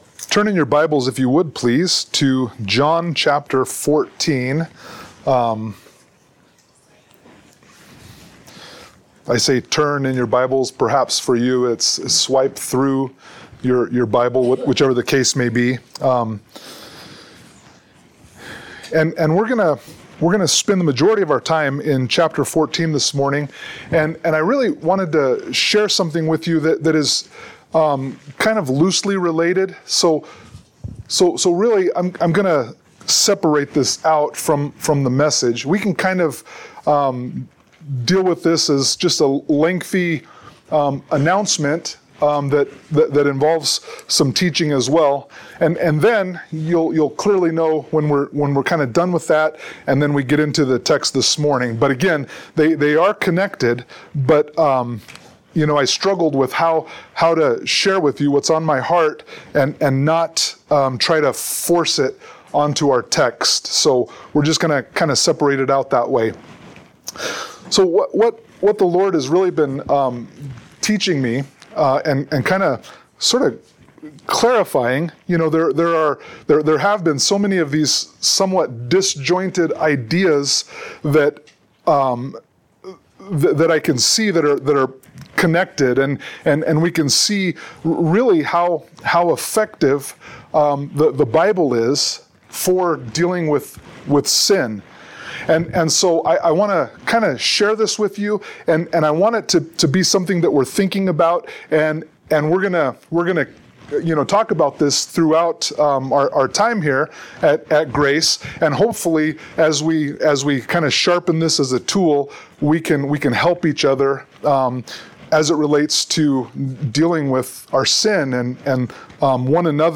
Sermon-8_25_24.mp3